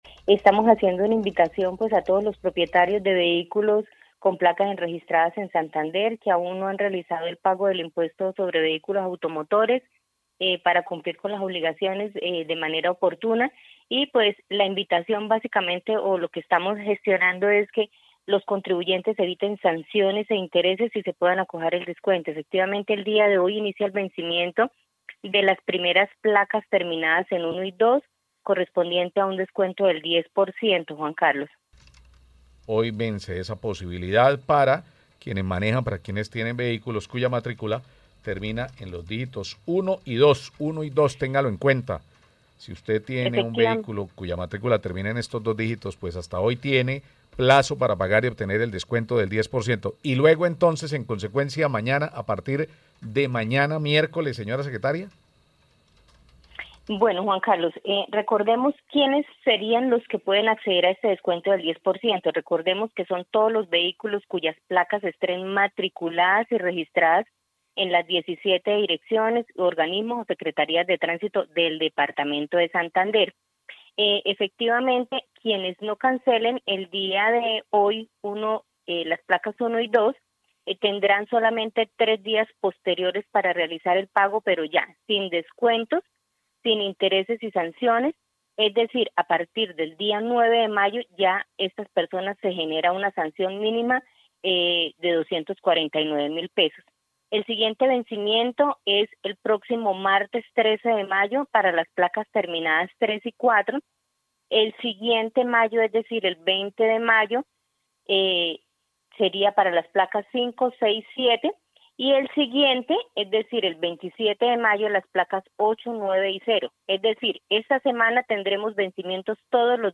Diana Durán, Secretaria de Hacienda de Santander